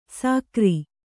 ♪ sākri